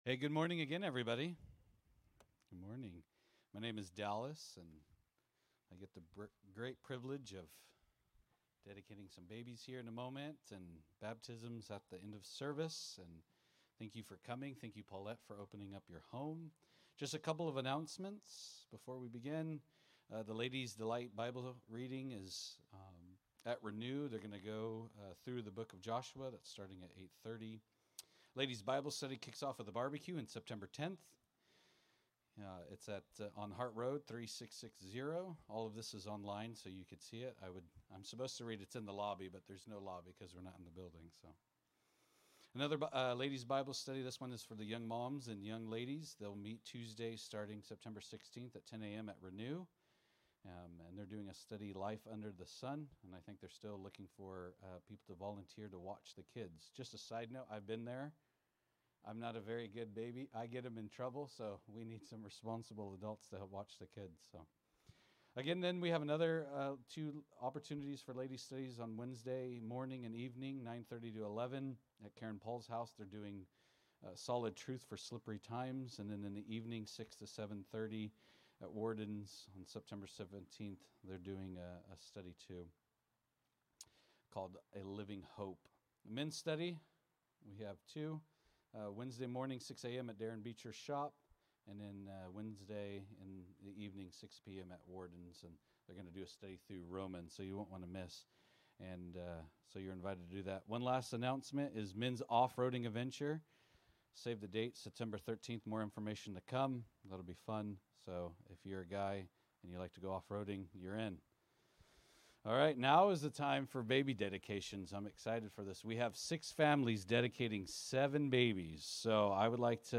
Special Messages Service Type: Sunday Morning « Genesis-In the Beginning